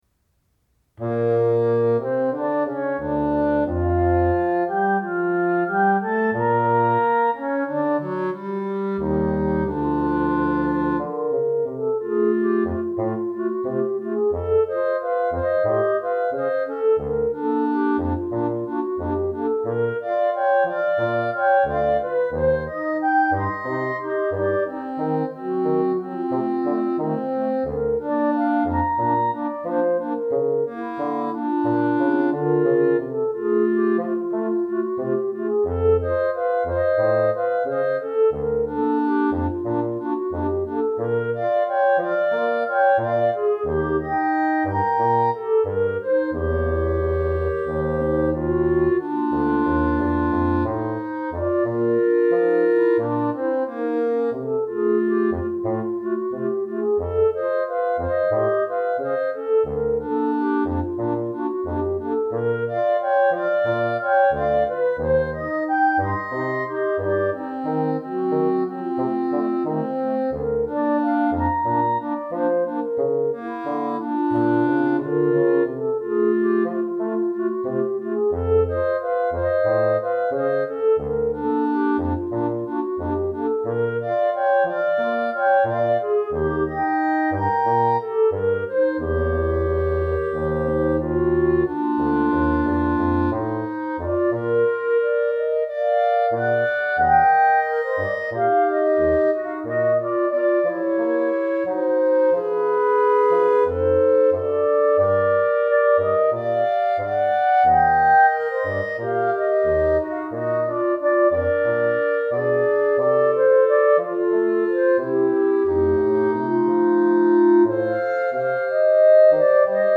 Clarinet Quartet +
A slow and poignant serenade with a gentle tango rhythm,